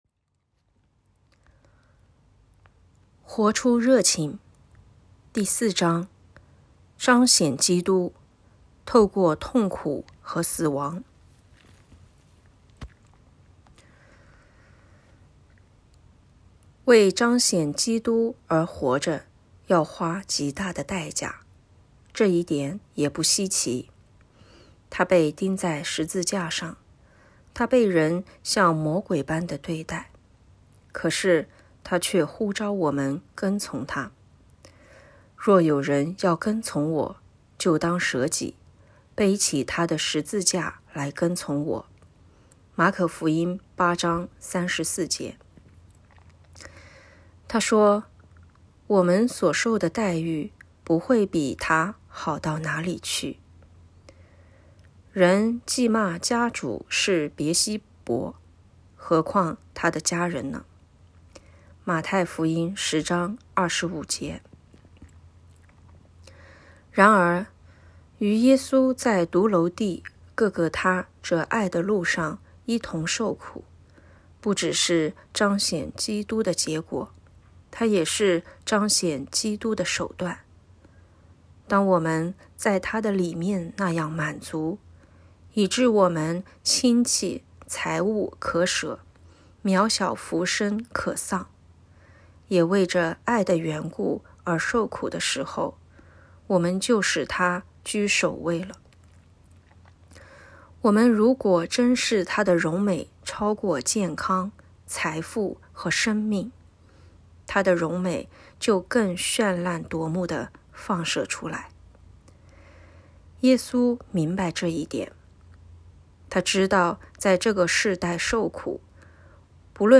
让我们一起陪你读好书，每周一章，思想生命的意义。 欢迎点击下方音频聆听朗读内容